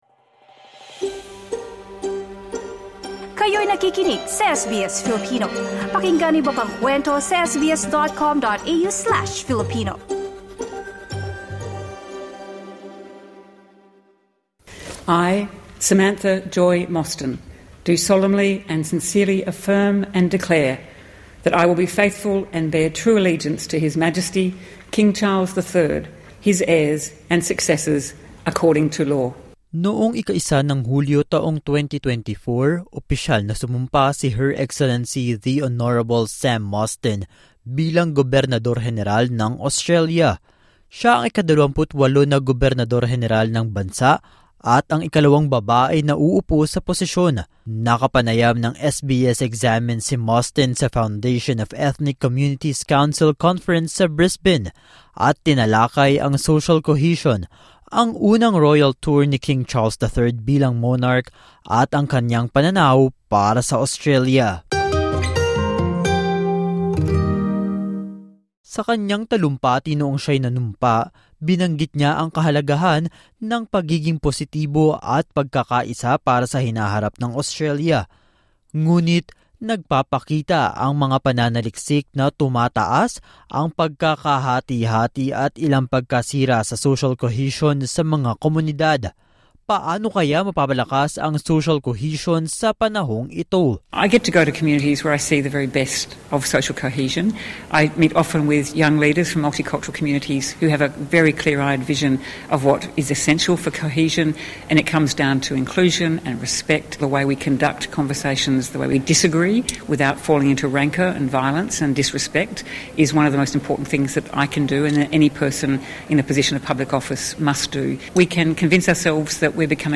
SBS Examines: ‘There is a pathway through for Australia’: Panayam kasama ang Governor-General